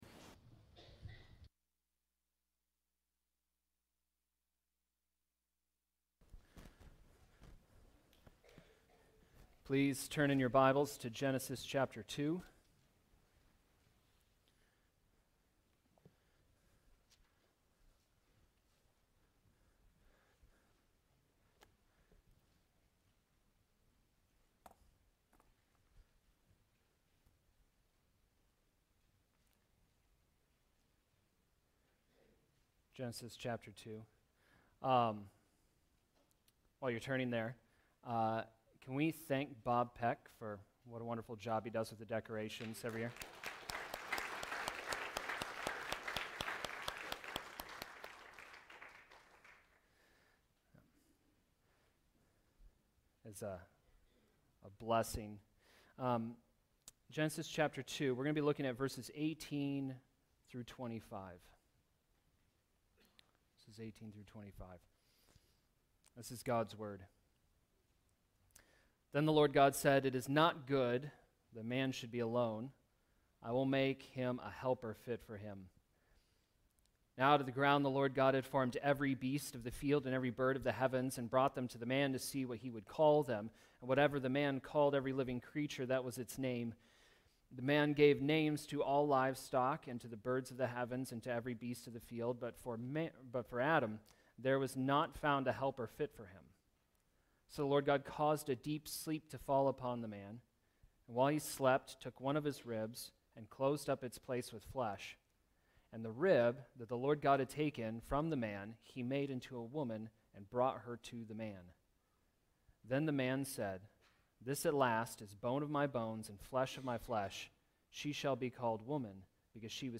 Genesis Sermons